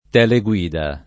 [ t H le gU& da ]